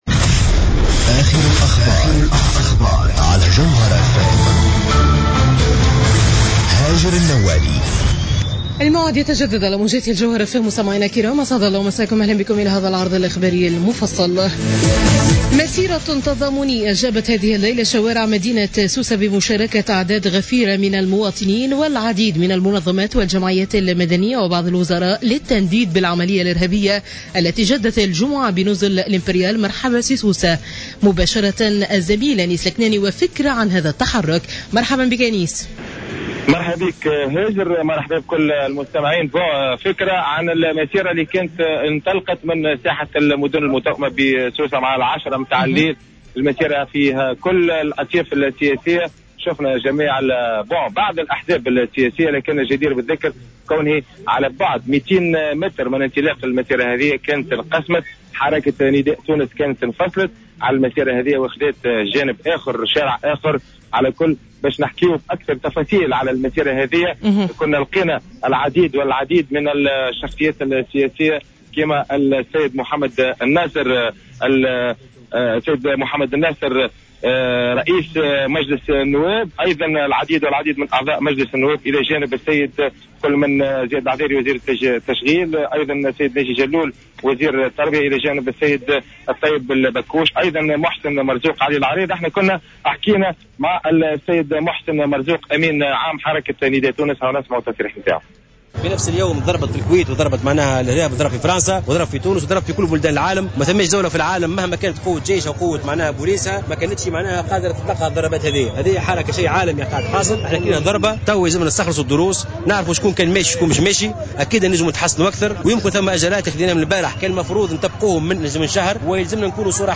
Journal info 00h00 du dimanche 28 juin 2015